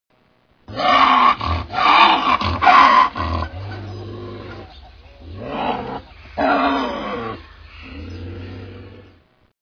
Испуганная рысь вопит